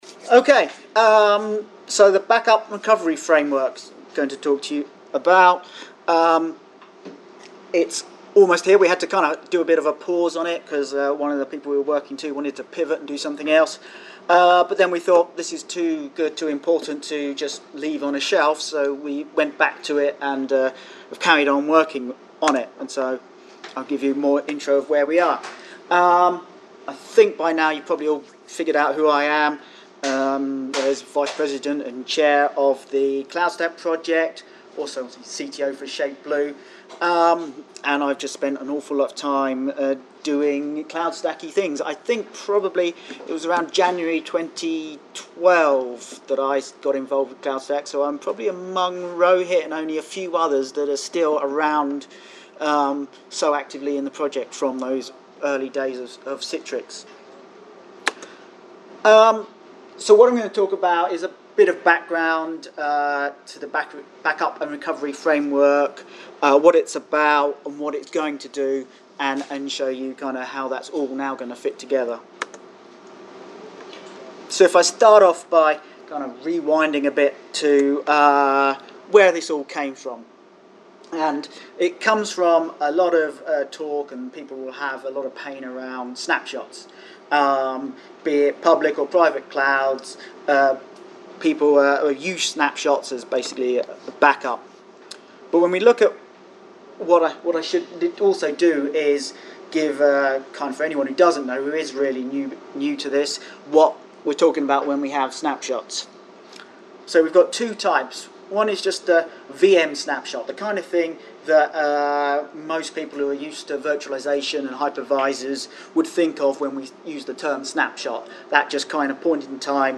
This presentation will demonstrate the long awaited Backup & Recovery Framework feature. The presentation will feature a real-world demonstration as well explain the design philosophy and how the feature has been built to be agnostic of the backend Backup and Recovery software in use.